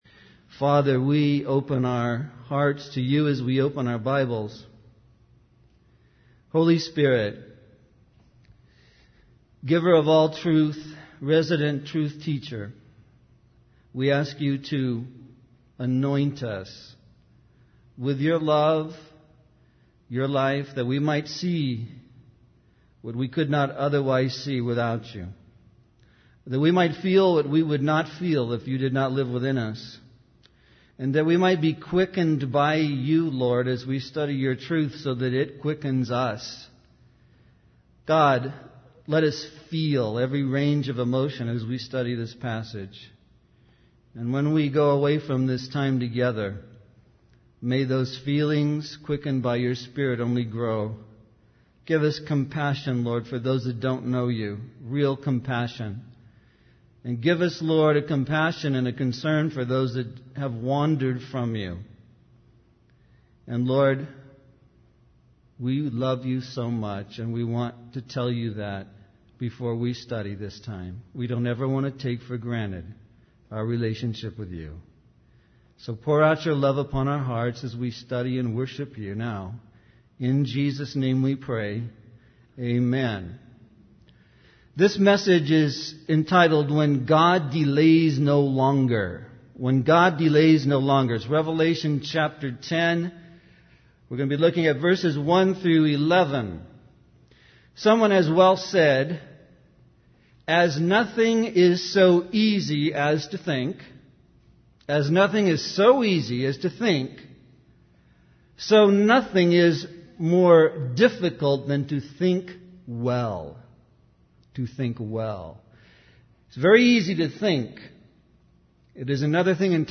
In this sermon, the speaker discusses a powerful angel described in Revelation 10.